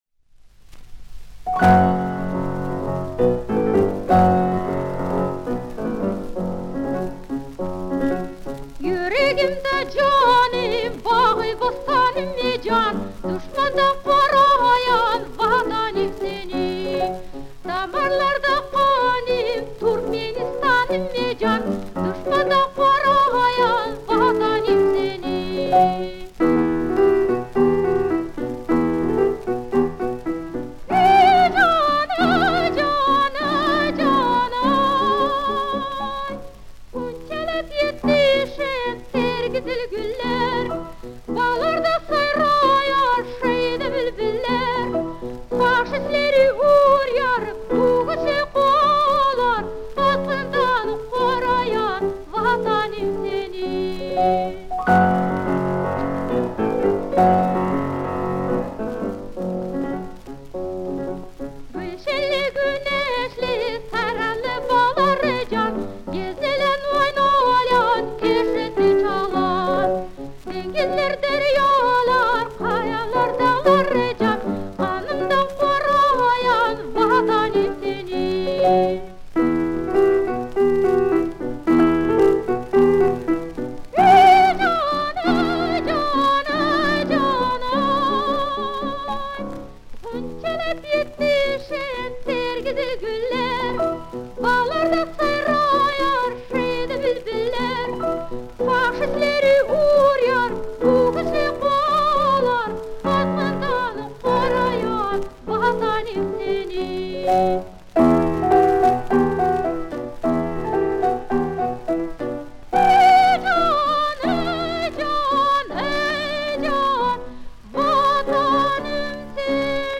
ф-но